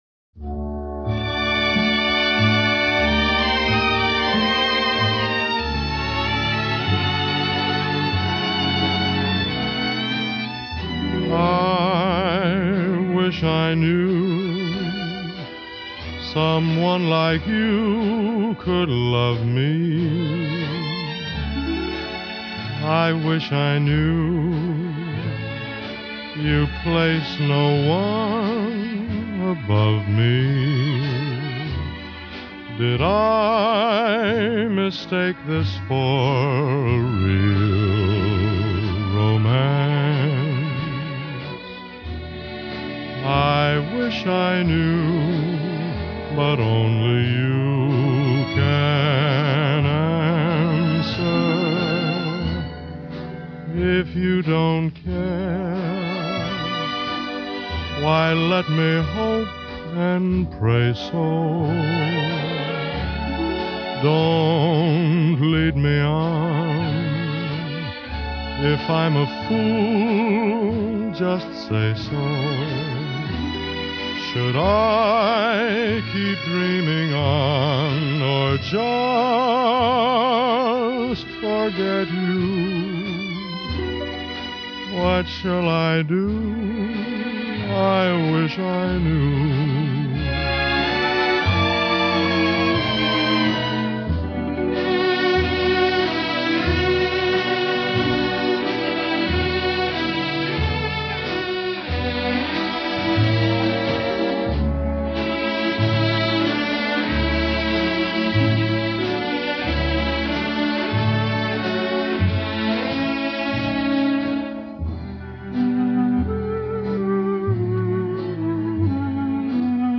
Обладатель баритона красивого глубокого тембра.